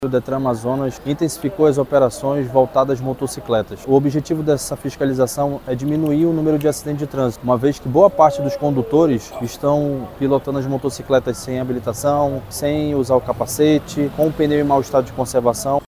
O diretor-presidente do Detran-AM, David Fernandes, destacou que o objetivo é proteger a população e reduzir acidentes.
Sonora-1-David-Fernandes.mp3